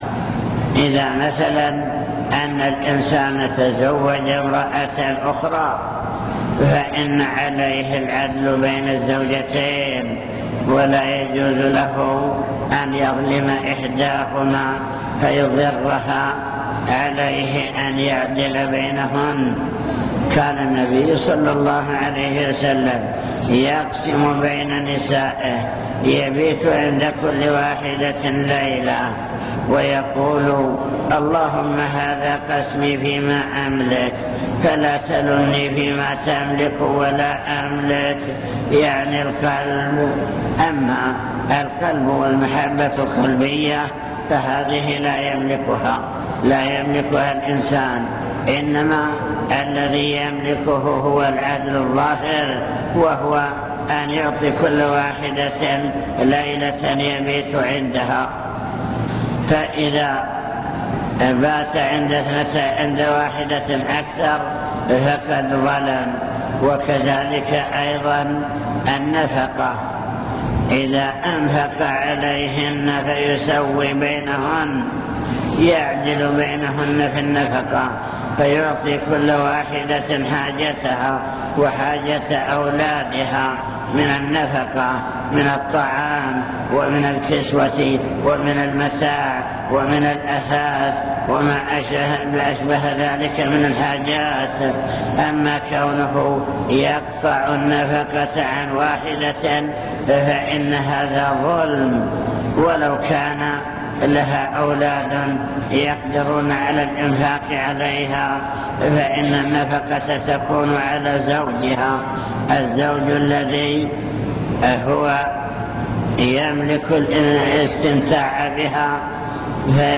المكتبة الصوتية  تسجيلات - محاضرات ودروس  محاضرة حول توجيهات في العقيدة والأسرة